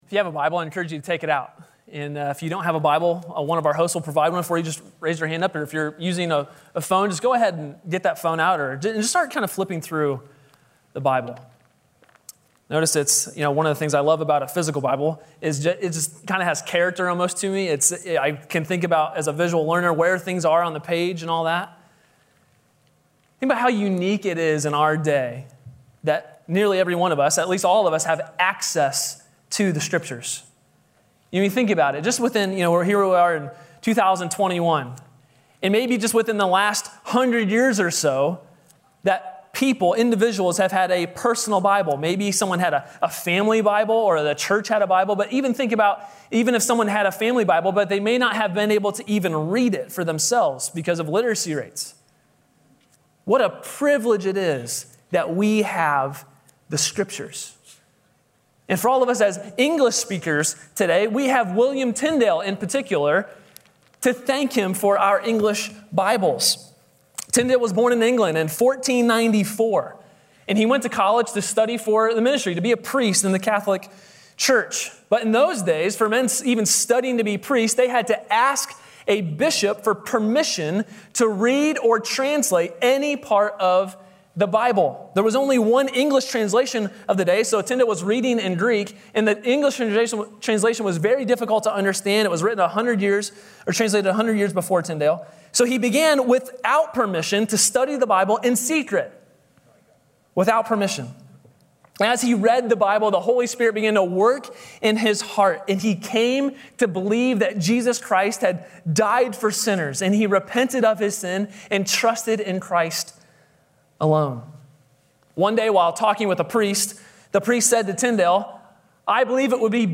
A sermon from the series "Something to Believe In."